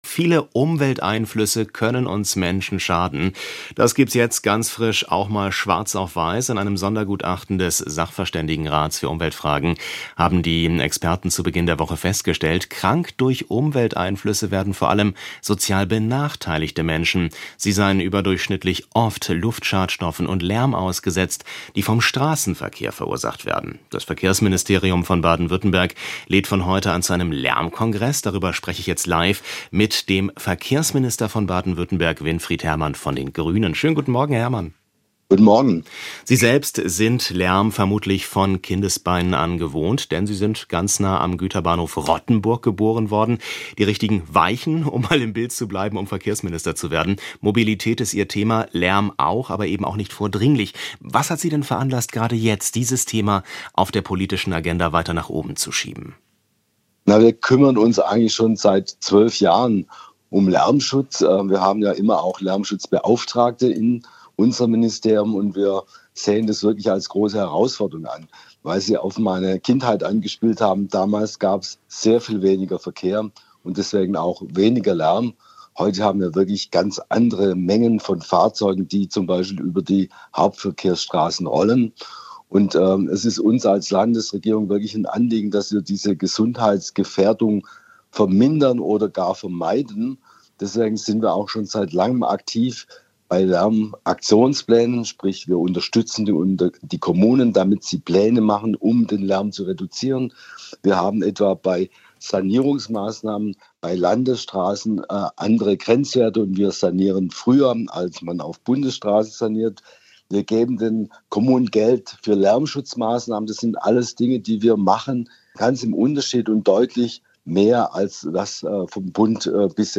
Baden-Württemberg will Lärm in Städten bekämpfen. Verkehrsminister Hermann erklärt in SWR Aktuell, wie er auch arme Menschen schützen will.